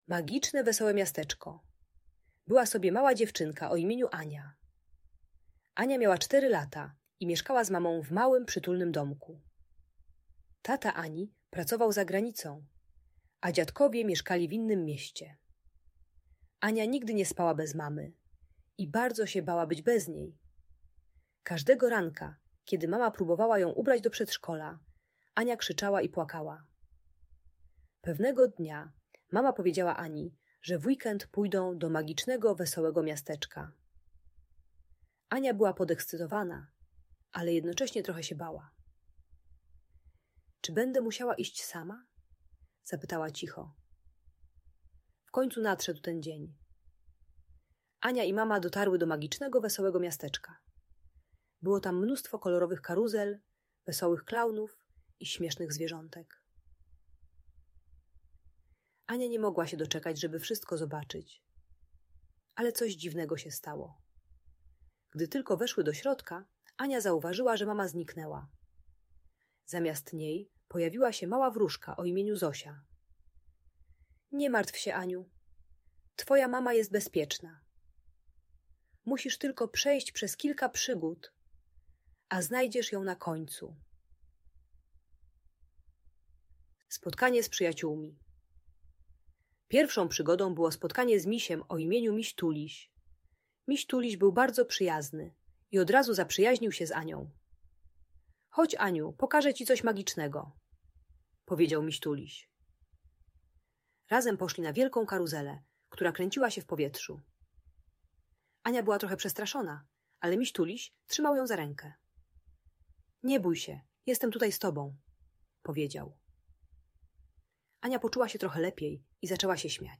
Magiczne Wesołe Miasteczko: Przygody Ani - Audiobajka